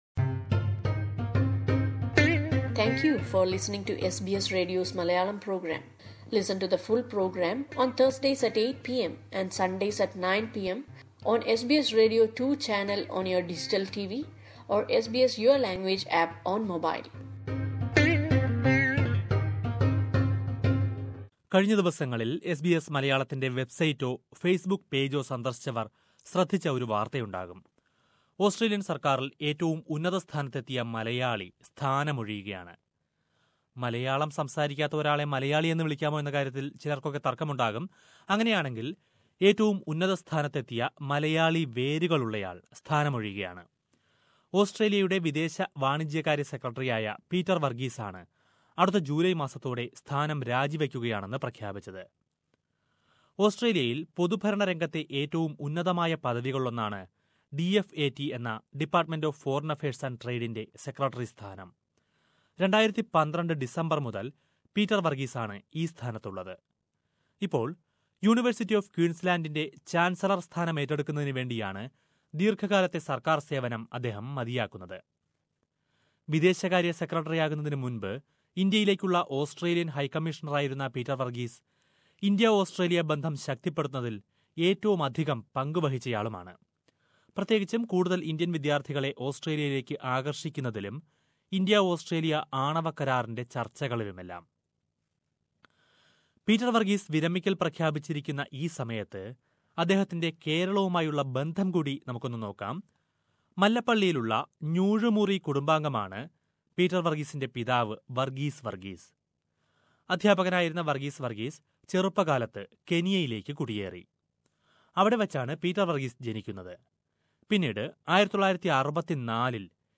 ഓസ്‌ട്രേലിയയില്‍ ഏറ്റവും ഉന്നത സ്ഥാനത്തെത്തിയ മലയാളിയാണ് പീറ്റര്‍ വര്‍ഗീസ്. ഓസ്‌ട്രേലിയന്‍ വിദേശകാര്യ സെക്രട്ടറിയായിരുന്ന പീറ്റര്‍ വര്‍ഗീസ്, എസ് ബി എസ് റേഡിയോയില്‍ മലയാളം പ്രക്ഷേപണം തുടങ്ങിയ സമയത്ത് ഞങ്ങളുമായി സംസാരിച്ചു.